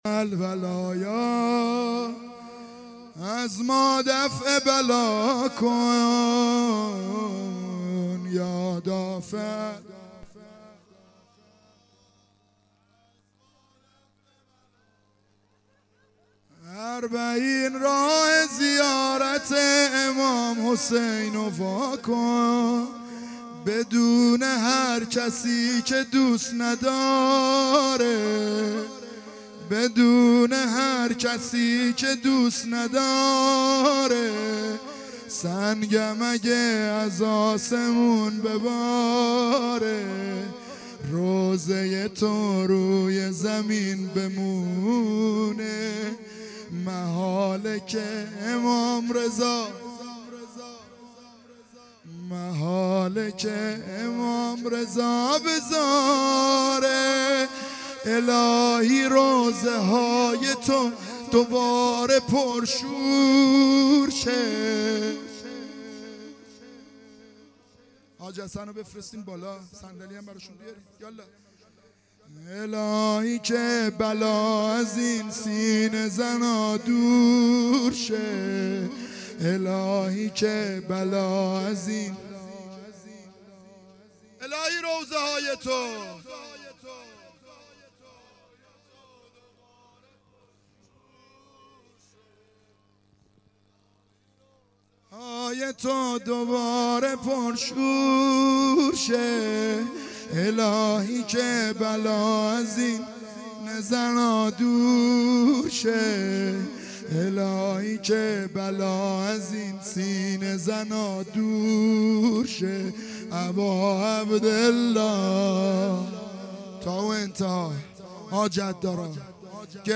عزاداری دهه اول محرم الحرام 1442